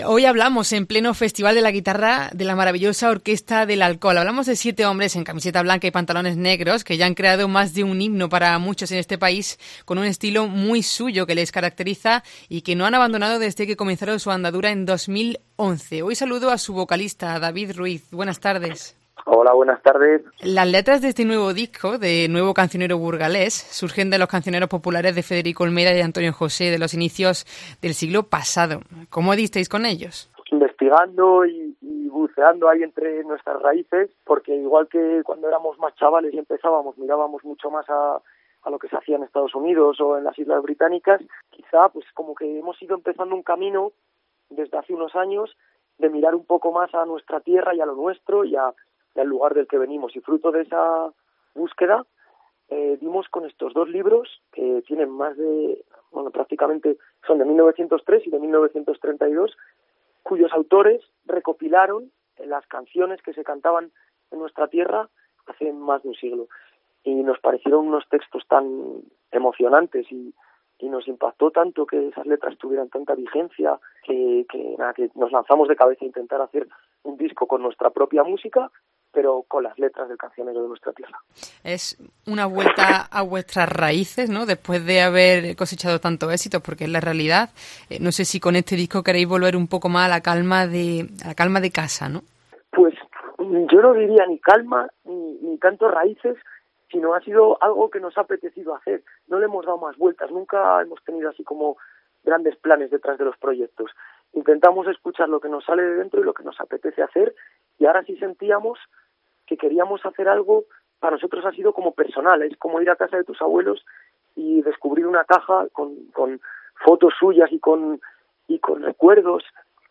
Entrevista a la Maravillosa Orquesta del Alcohol en COPE Córdoba